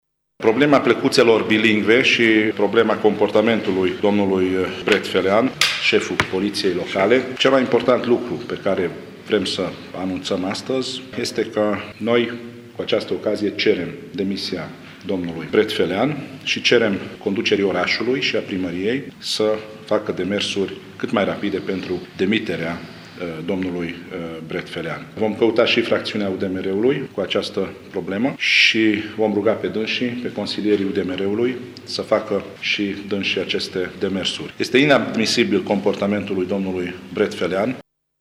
Declaraţia a fost făcută, astăzi, de preşedintele PCM, Biró Zsolt.